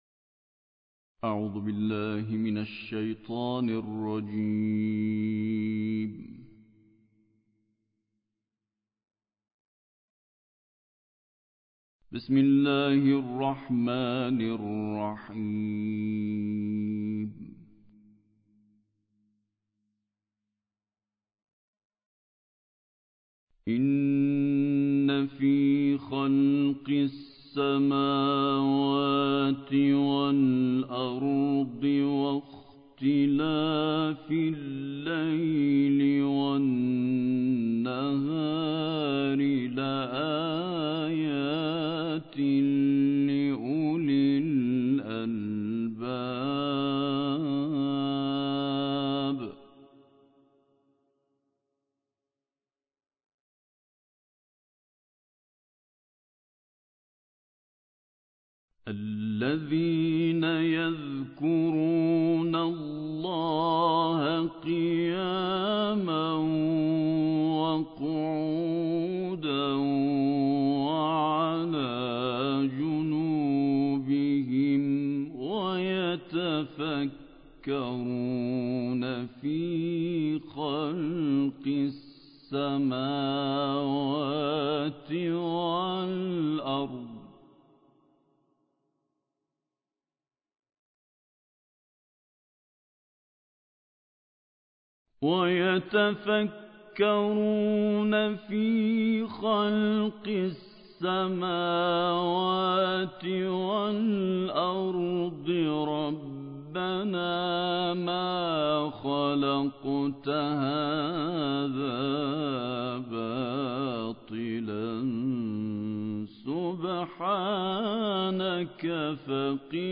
دانلود قرائت سوره آل عمران آیات 190 تا آخر - استاد سعید طوسی